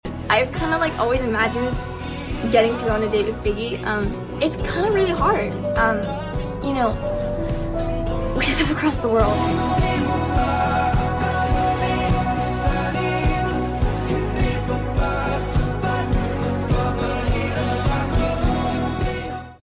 TIKTOK RUINED THE QUALITY